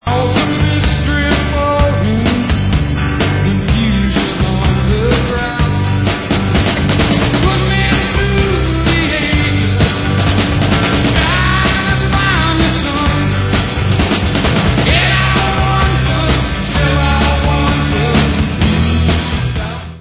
Remasterovaná verze živého alba z roku 1970